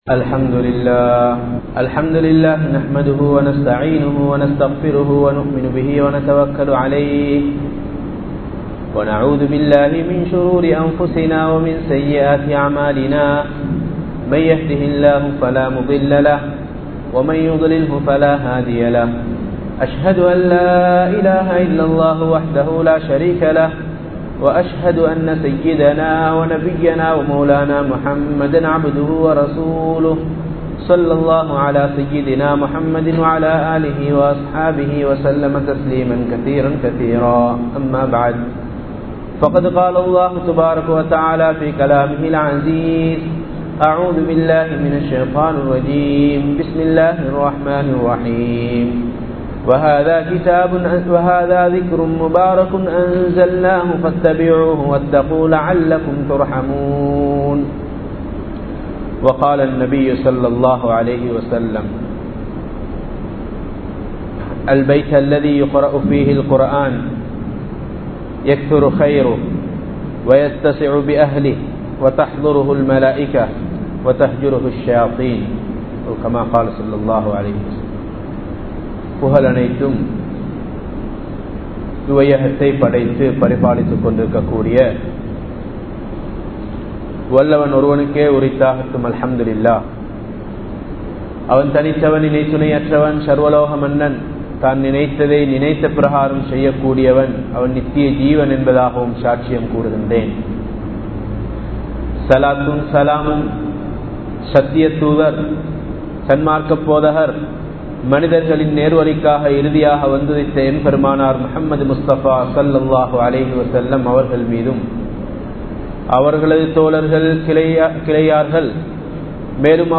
வாழ்க்கையில் வெற்றி வேண்டுமா? | Audio Bayans | All Ceylon Muslim Youth Community | Addalaichenai
Colombo 03, Kollupitty Jumua Masjith